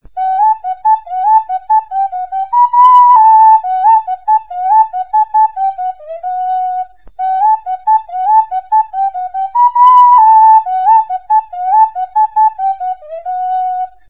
OKARINA; OCARINA | Soinuenea Herri Musikaren Txokoa
Aerophones -> Flutes -> Ocarina
Marroi argiz margotutako buztinezko okarina arrunta da.